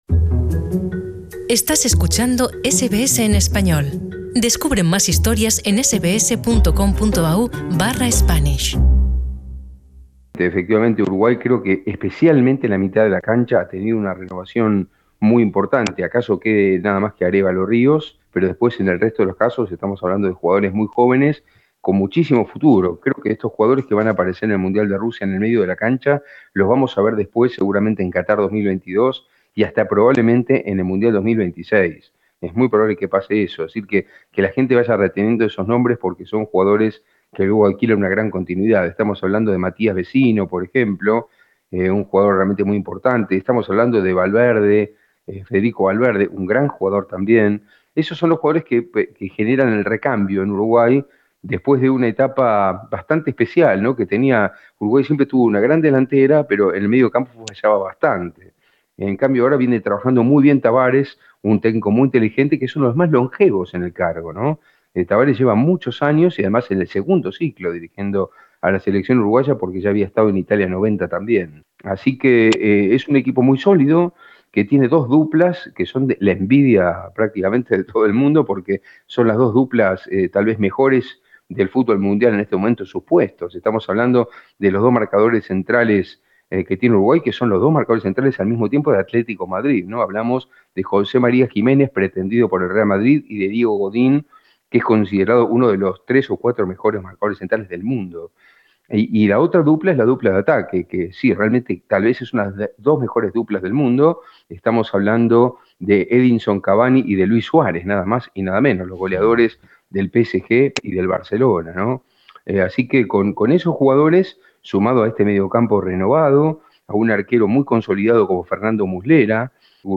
análisis
quien conversa desde Moscú